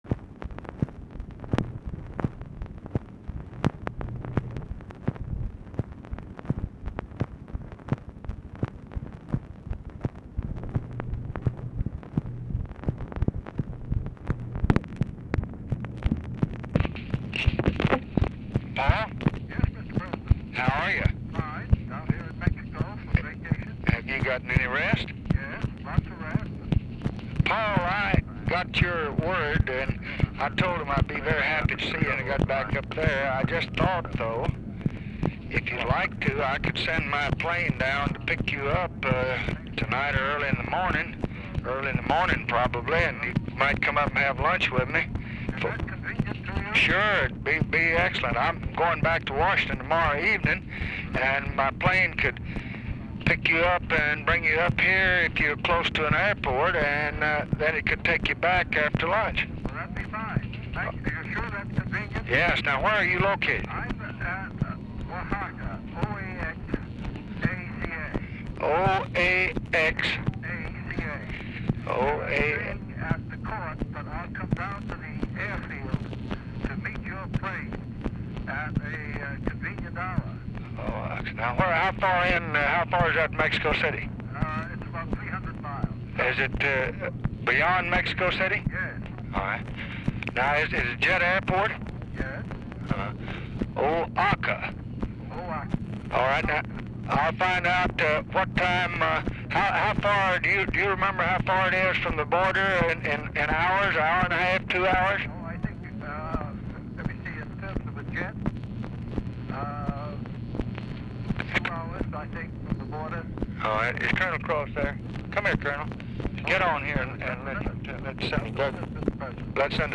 DOUGLAS IS DIFFICULT TO HEAR
Format Dictation belt
Location Of Speaker 1 LBJ Ranch, near Stonewall, Texas
Specific Item Type Telephone conversation